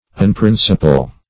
Search Result for " unprinciple" : The Collaborative International Dictionary of English v.0.48: Unprinciple \Un*prin"ci*ple\, v. t. [1st pref. un- + principle.]
unprinciple.mp3